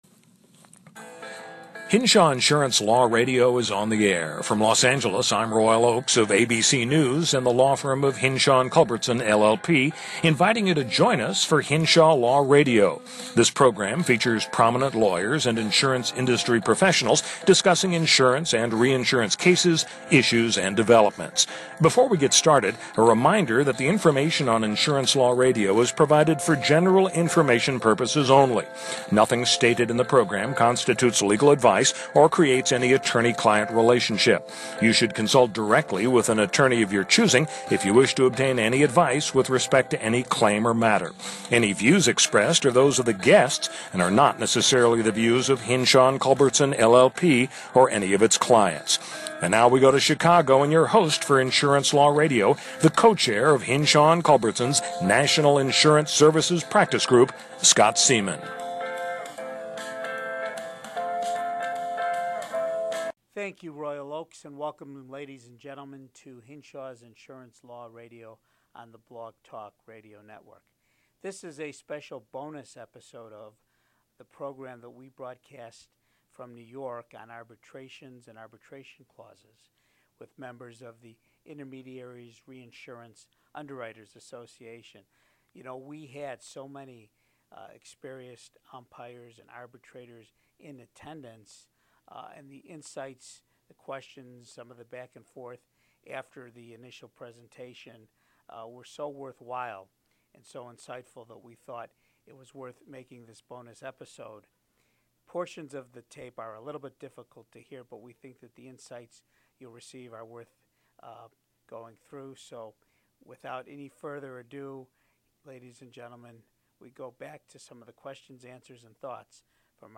The episode was recorded at a meeting of the Intermediaries and Reinsurance Underwriters Association.
Numerous experienced reinsurance arbitrators and umpires were in attendance. In the question and answer session, they raise issues and provide insights concerning reinsurance arbitrations that you may find to be interesting.